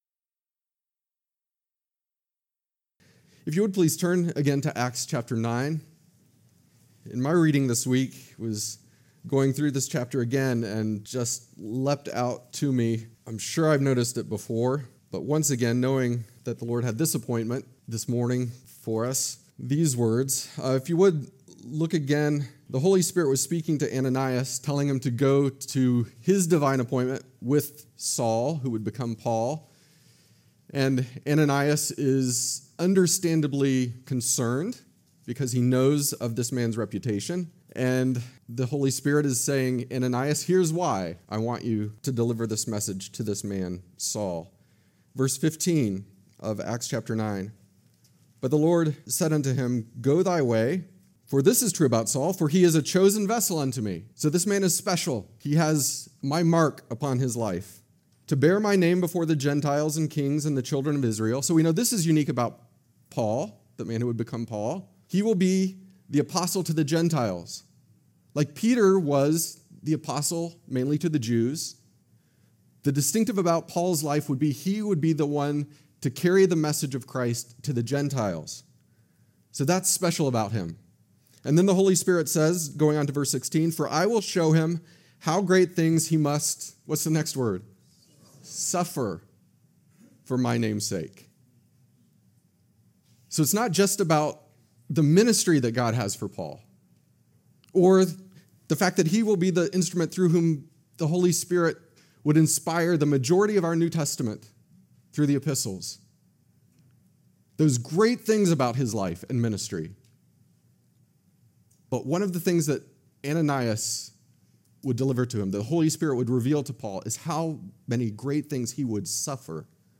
Sermons from visiting guests or other speakers